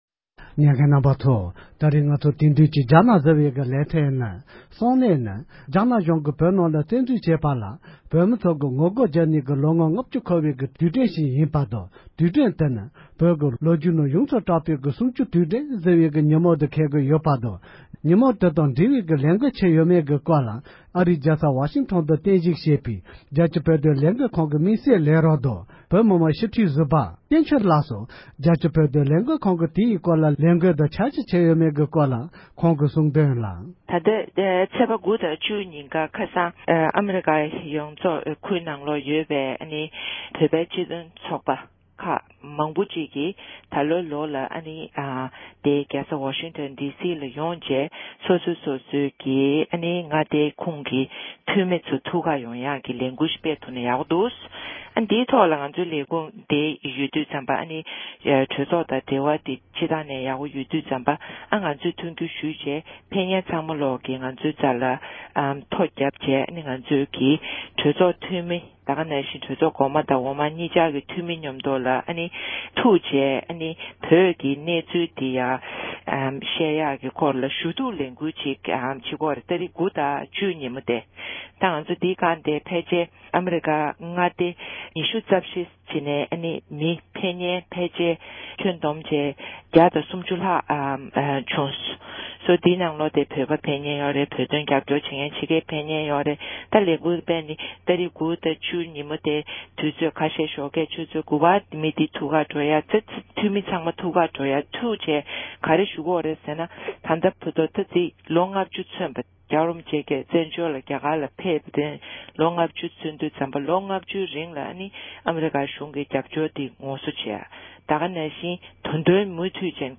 ཨ་རི་ནང་ཡོད་པའི་བོད་མི་ཚོས་བོད་དོན་ལས་འགུལ་སྤེལ་ཕྱོགས་སྐོར་འབྲེལ་ཡོད་མི་སྣ་ཞིག་གིས་འགྲེལ་བརྗོད་གནང་བ།
སྒྲ་ལྡན་གསར་འགྱུར། སྒྲ་ཕབ་ལེན།